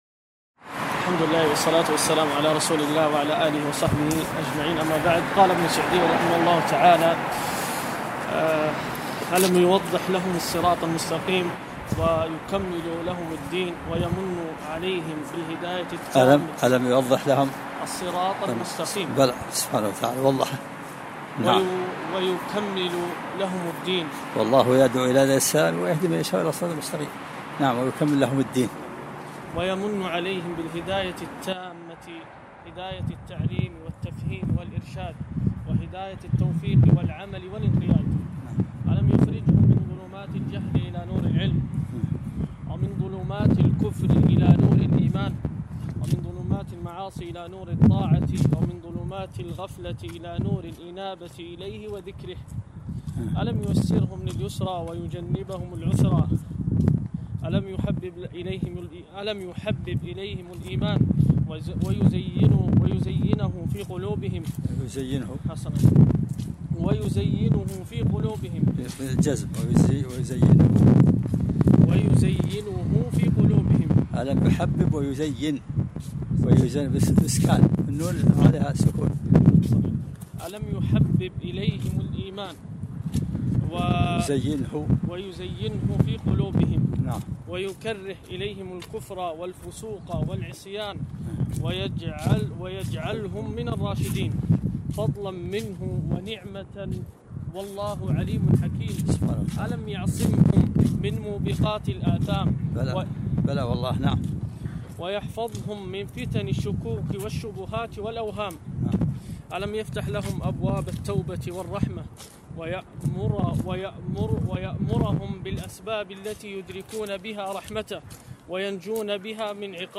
الدرس الرابع - فتح الرحيم الملك العلام في العقائد